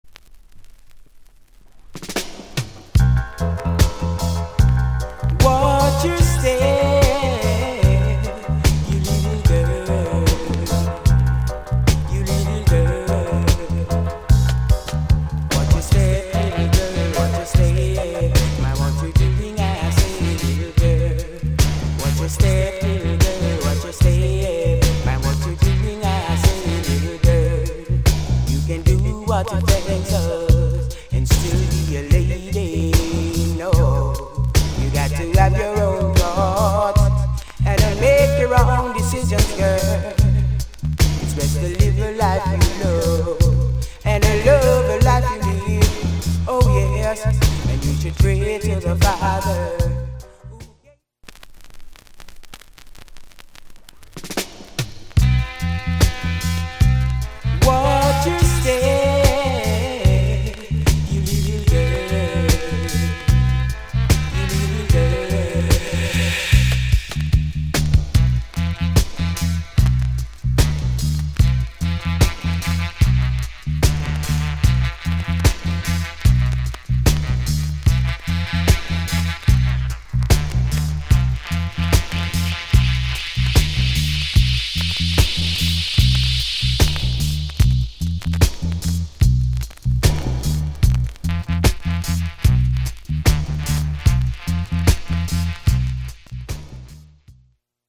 Production Genre Reggae80sEarly / Male Vocal